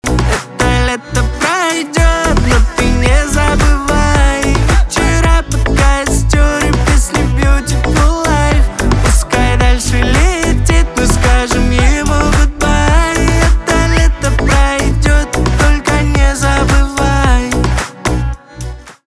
поп
аккордеон
басы
грустные , чувственные